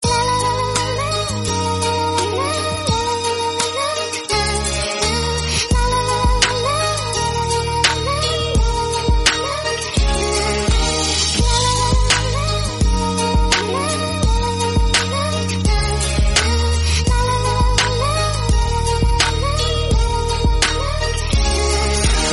P O L I C E